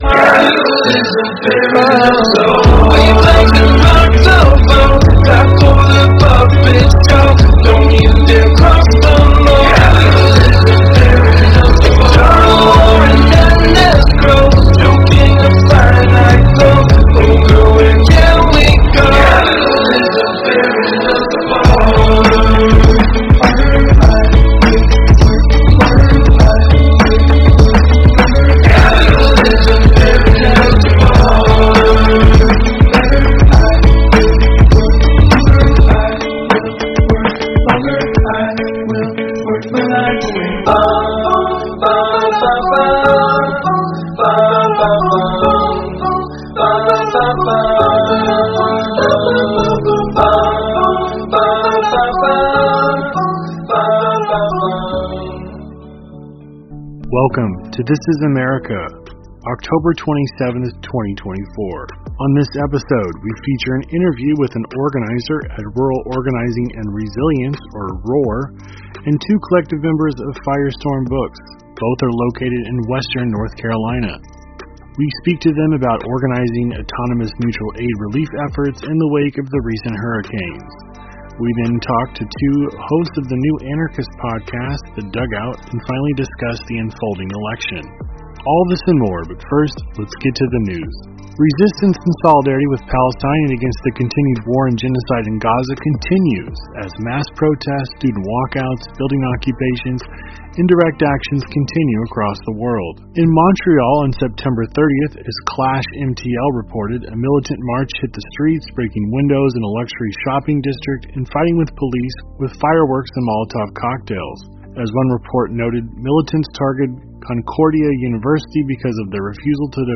Don't miss our latest episode of This Is America, featuring an interview with someone from Rural Organizing and Resiliance (ROAR) and volunteers @ firestorm in # Asheville . We discuss how hundreds of people across the city came together following # HurricaneHelene in mass meetings and helped to organize autonomous disaster relief and mutual aid. ROAR speaks about the challenges of mobilizing in rural areas.